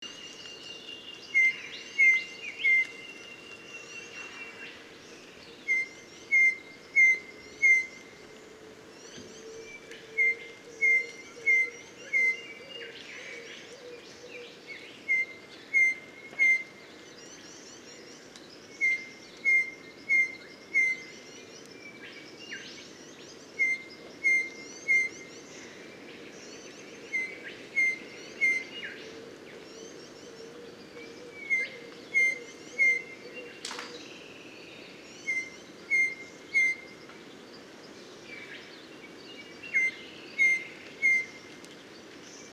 Eastern Yellow Robin
Eopsaltria australis
Songs & Calls
The voice includes a variety of high bell-like piping, a repeated 'chop chop' and some scolding notes.
Eastern-Yellow-Robin-Eopsaltria-australis-chrysorrhos.mp3